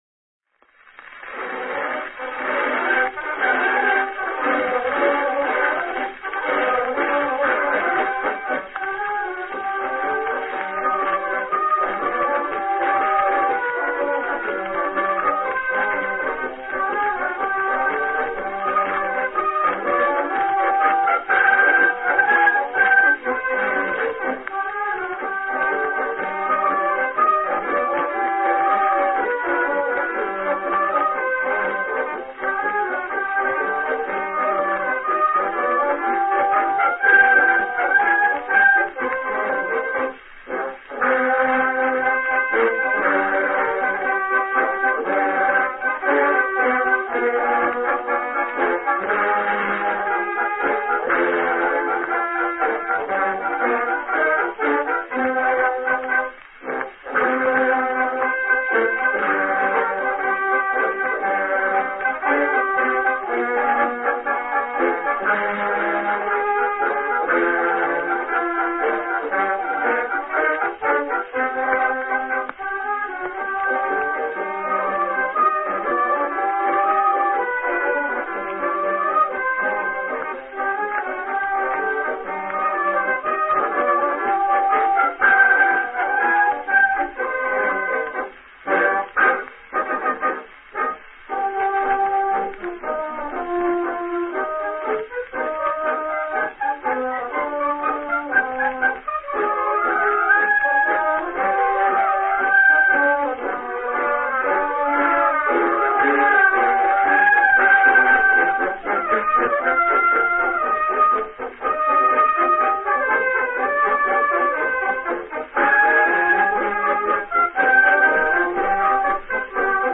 Духовой оркестр 36-го польского пехотного полка:
Грампластинка